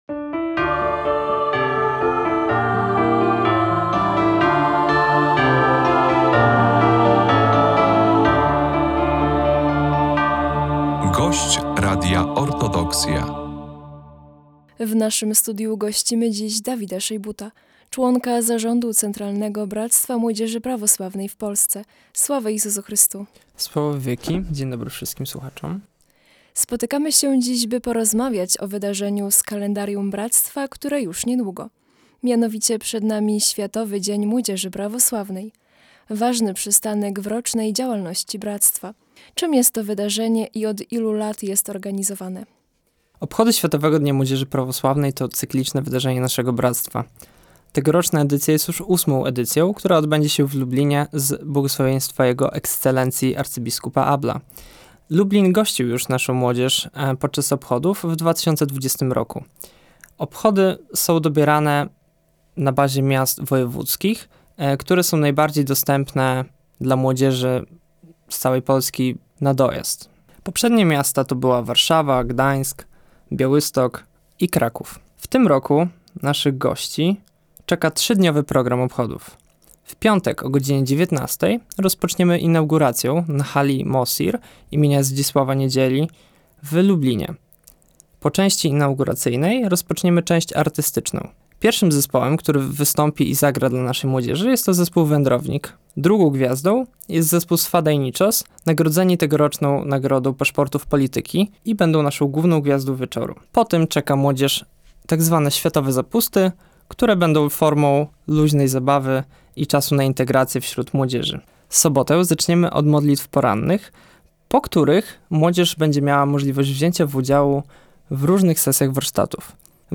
Młodzież prawosławna we wspólnym świętowaniu - rozmowa
W naszym studiu gościliśmy